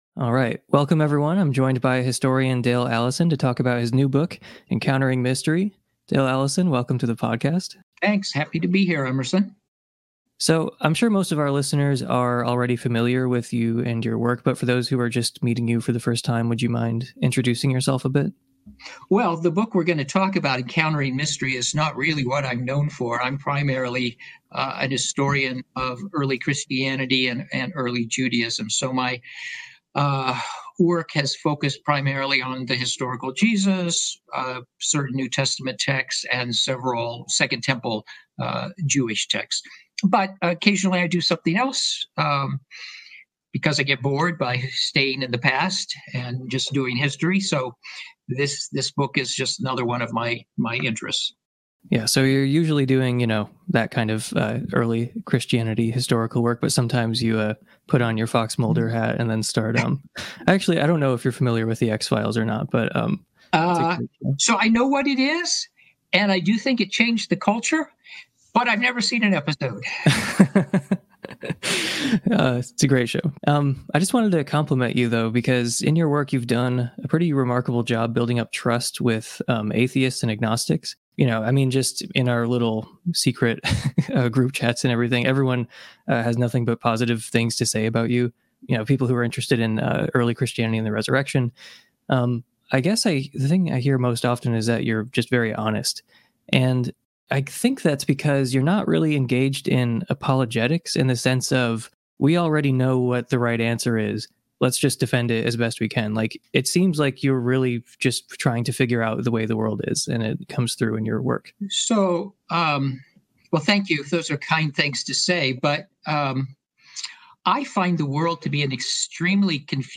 but I’ll save it for the interview